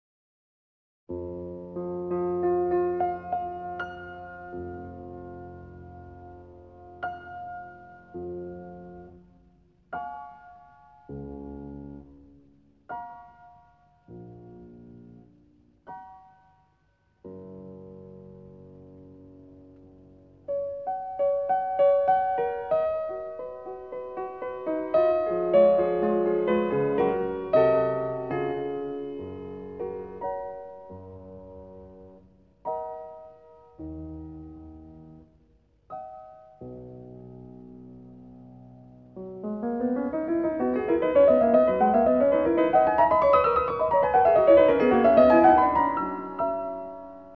تجدون هنا الأداجيو معزوفةً من ألفريد بريندل، و كذلك المقاطع المقتبسه القادمه هي من نفس التسجيل :
و بعد ان اختتمت هذه الأداچيو الاستثنائيه بخاتمه هادئه لاتكاد تلاحظ ابتدأت الحركة الرابعه و كأنها إفاقة من حلم عميق بنوتة F معزوفة على كل الدرجات الممكنه من اخفضها الى اعلاها ثم تعزف كوردات عديدة تبدو وكأنها محاولة ايجاد المفتاح الصحيح
F# ؟ جيد،  ثم ينساب لحن سريع على هذا المفتاح ليقف فجأه و كأن المفتاح الذي اختاره لم يكن مناسبا فيعود لاختياراته
hammerklavier-fugue-1.mp3